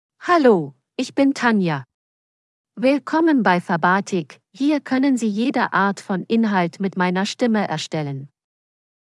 FemaleGerman (Germany)
Tanja is a female AI voice for German (Germany).
Voice sample
Listen to Tanja's female German voice.
Female